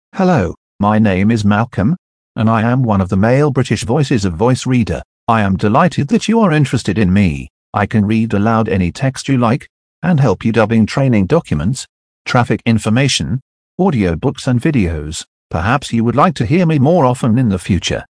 Voice Reader Studio 22 English (British)
Professionelle Sprachausgabe zum Vorlesen und Vertonen beliebiger Texte
Die Stimmen klingen so natürlich, dass sie von menschlichen Sprechern kaum noch zu unterscheiden sind.
Professionelle, natürlich klingende männliche und weibliche Stimmen in vielen Sprachen, die kaum mehr von einem menschlichen Sprecher zu unterscheiden sind.